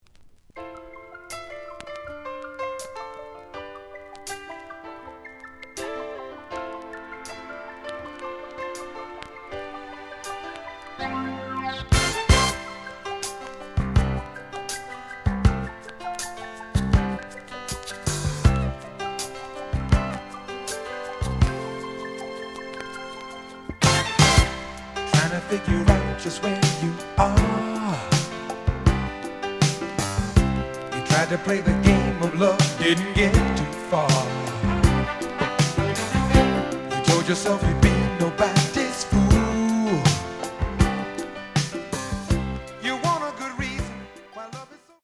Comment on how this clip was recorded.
The audio sample is recorded from the actual item. Some click noise on beginning of B side, but almost good.